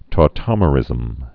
(tô-tŏmə-rĭzəm)